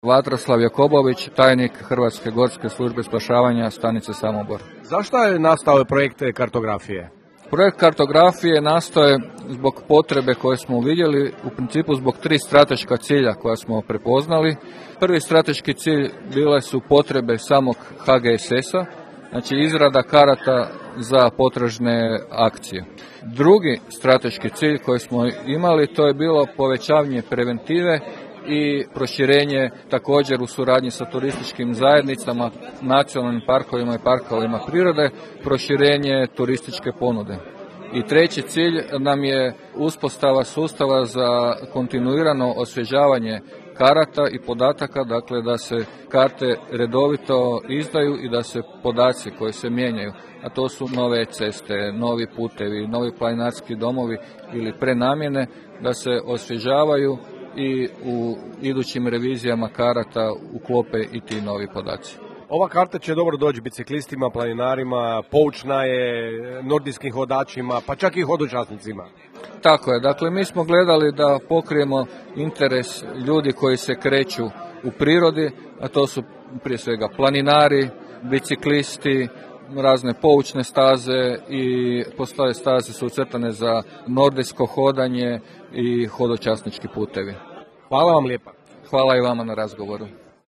Osim što će koristiti povećanju sigurnosti kretanja na teško prohodnim i nepristupačnim predjelima, HGSS-ova karta “Žumberačko gorje, turističko – planinarski zemljovid” promiče i prirodne, kulturne i sportsko-rekreativne vrijednosti ruralnog žumberačkog područja, a sve to osobito raduje načelnika općine Žumberak – Zdenka Šiljka, koji je prisustvovao promociji karte: